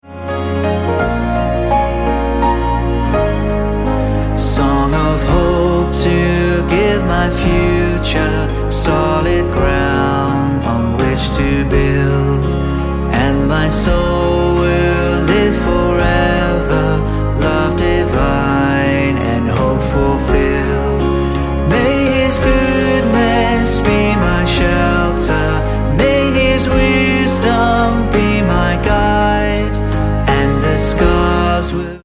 C#